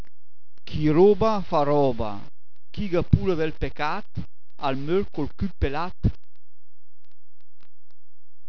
Se hai un browser che supporta i file .wav, cliccando sui proverbi scritti in bergamasco potrai ascoltarne anche la pronuncia, almeno per i primi 80 .